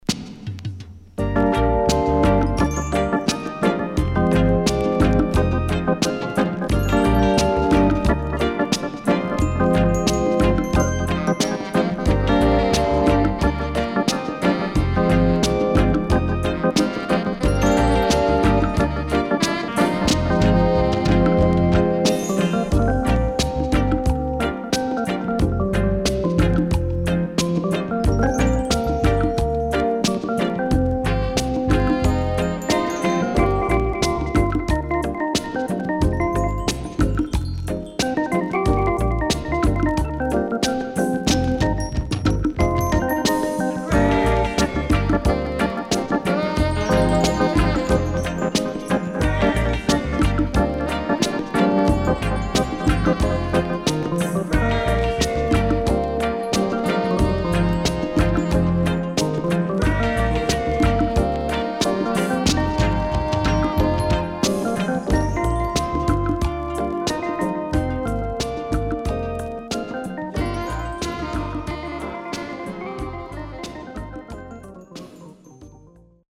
【12inch】
Urban Lovers Vocal & Great Organ Inst
SIDE A:序盤ジリジリしたノイズあり、所々チリノイズ入ります。